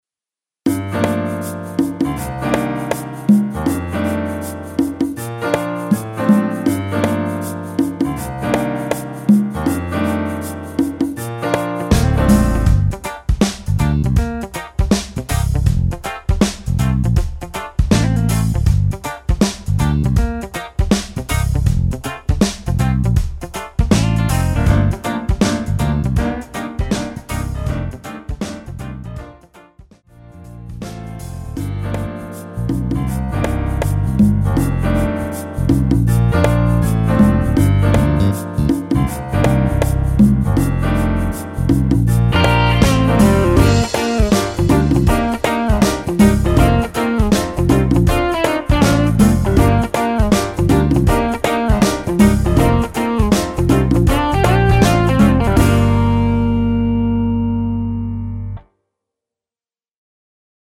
엔딩이 페이드 아웃이라 라이브 하시기 좋게 엔딩을 만들어 놓았습니다.(미리듣기 참조)
Gm
◈ 곡명 옆 (-1)은 반음 내림, (+1)은 반음 올림 입니다.
앞부분30초, 뒷부분30초씩 편집해서 올려 드리고 있습니다.
중간에 음이 끈어지고 다시 나오는 이유는